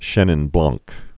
(shĕnĭn blängk)